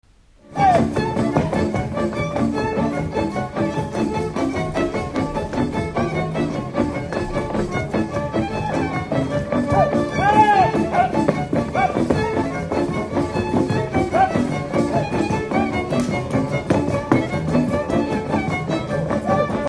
Dallampélda: Hangszeres felvétel
Dunántúl - Sopron vm. - Szany